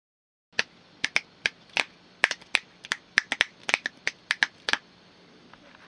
啪嗒啪嗒的手指
描述：一系列手指按扣。一次单击然后突然爆发。
Tag: 点击 手指 手指 捕捉 弗利